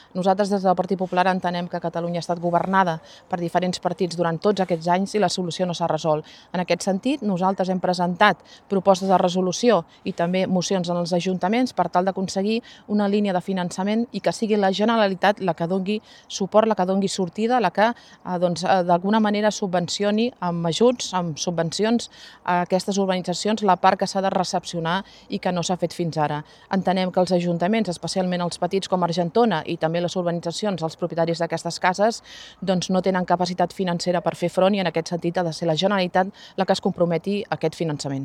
La formació va organitzar aquest dimarts a la tarda un acte a Can Raimí que va reunir una cinquantena de veïns i que va servir per posar sobre la taula el conflicte de les urbanitzacions no recepcionades.
Durant la seva intervenció, la diputada Eva García va defensar que la solució al conflicte ha de passar per la implicació de la Generalitat, a qui atribueix un paper clau per desencallar la situació de les urbanitzacions.